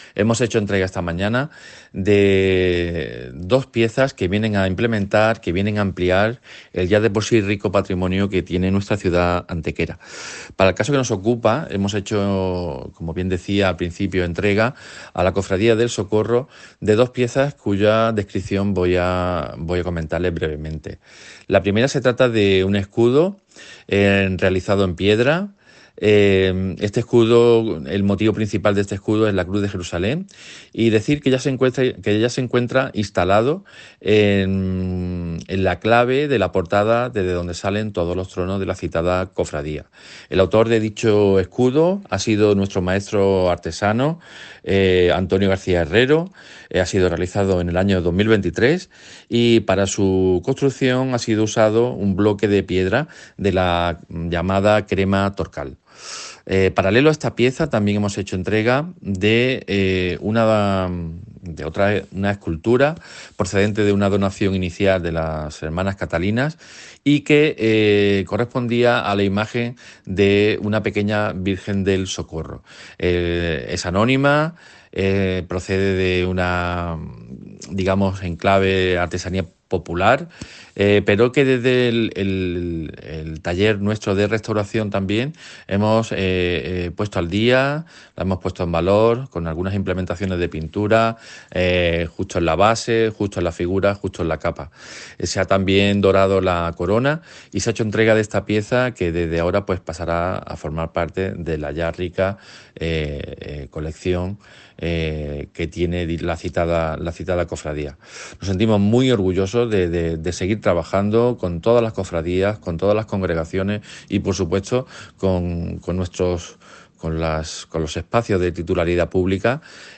El concejal delegado de Patrimonio Histórico del Ayuntamiento de Antequera, José Medina Galeote, informa de dos actuaciones que se han realizado desde dicha área municipal para la Archicofradía del Socorro.
Cortes de voz